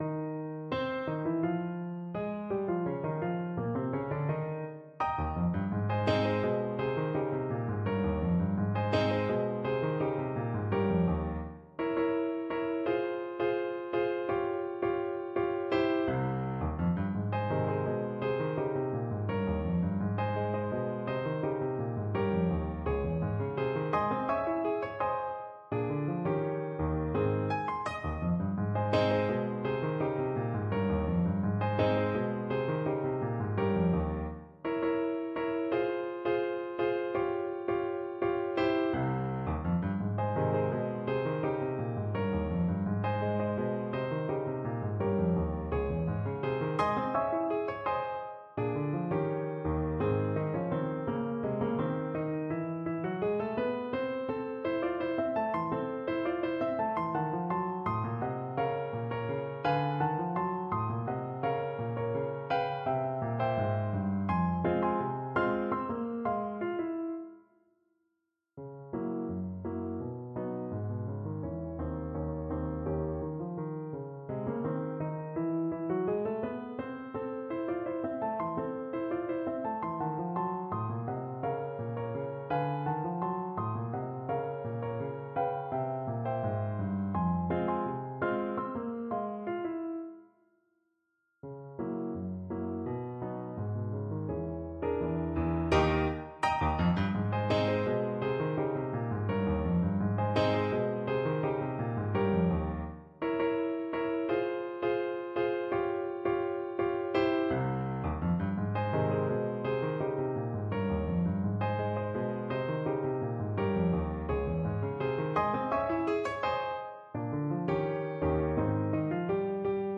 Free Sheet music for Clarinet
Clarinet
= 84 Slow March Time
2/4 (View more 2/4 Music)
Ab major (Sounding Pitch) Bb major (Clarinet in Bb) (View more Ab major Music for Clarinet )
Jazz (View more Jazz Clarinet Music)